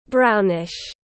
Brownish /ˈbraʊ.nɪʃ/